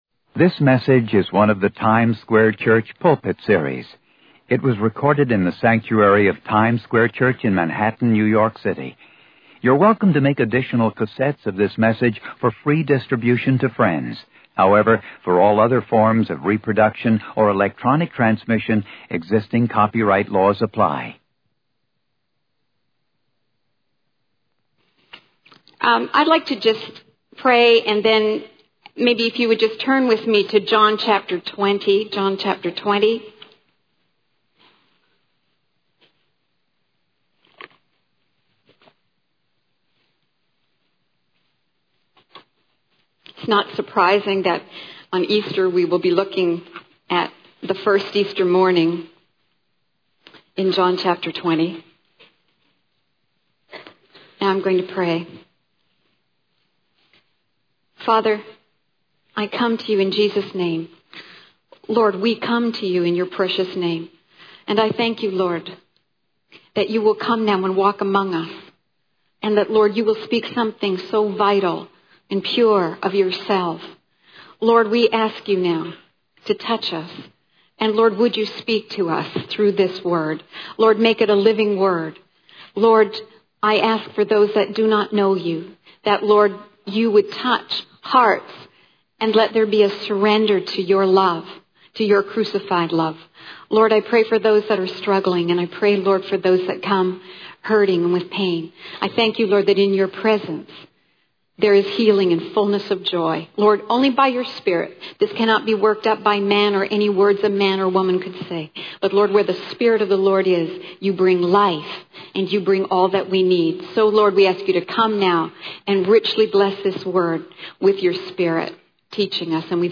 In this sermon, the speaker focuses on the events of the first Easter morning as described in John chapter 20.
It was recorded in the sanctuary of Times Square Church in Manhattan, New York City.